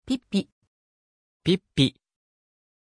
Pronunciation of Pippi
pronunciation-pippi-ja.mp3